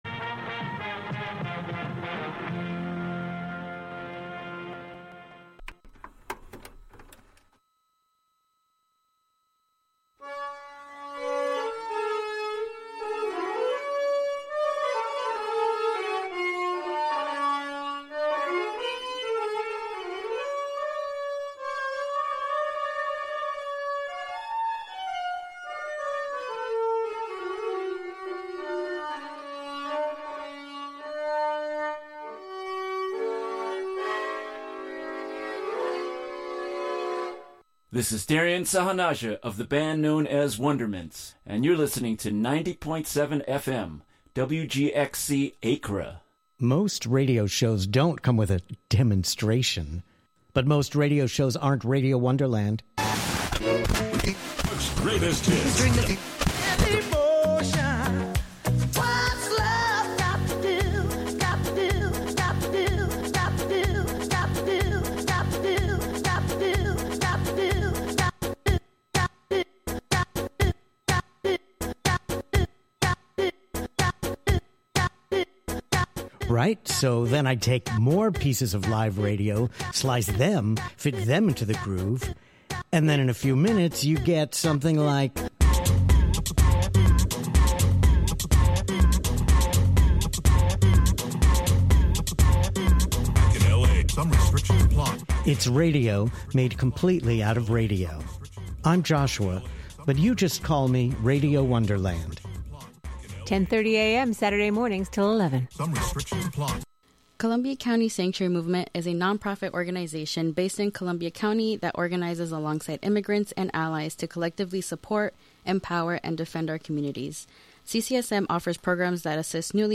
rare, unusual, fantastic shellac